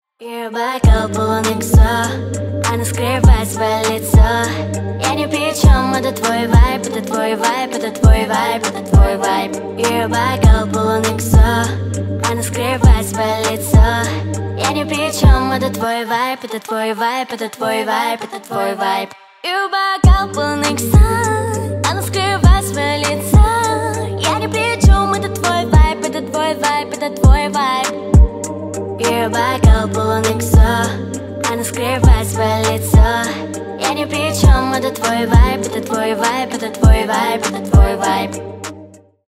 Поп Музыка
кавер